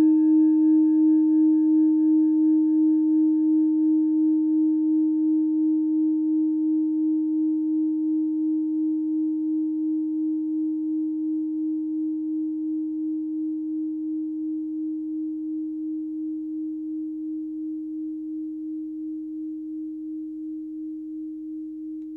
Kleine Klangschale Nr.33 Nepal, Planetentonschale: Wasserstoffgamma
Die Klangschale hat bei 312.01 Hz einen Teilton mit einer
Die Klangschale hat bei 314.94 Hz einen Teilton mit einer
Die Klangschale hat bei 895.75 Hz einen Teilton mit einer
kleine-klangschale-33.wav